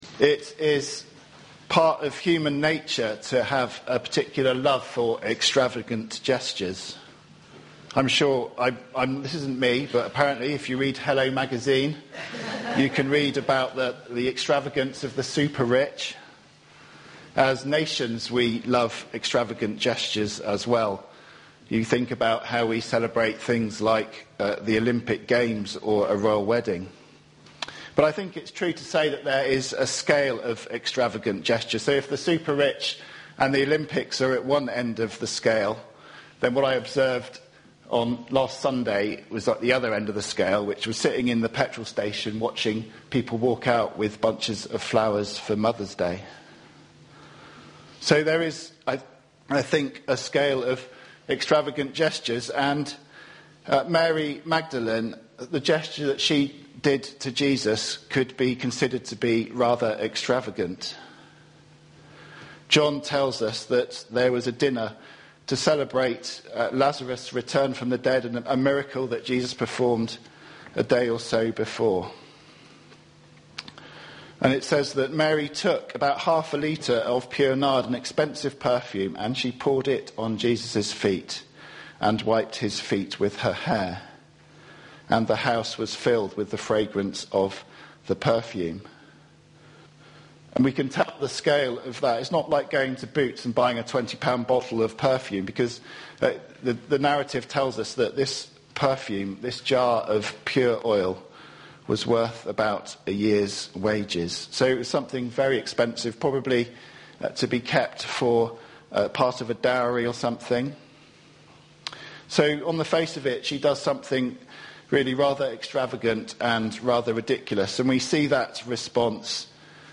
Sermon-7-April-2019.mp3